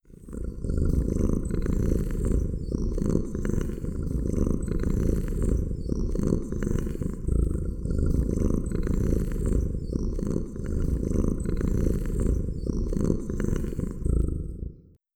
neko-purr.wav